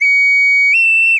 闹钟唤醒的声音
描述：闹钟唤醒的声音
标签： 鸣笛 警报 铃声 闹钟 蜂鸣器 时钟 早上 醒来 电子 唤醒
声道立体声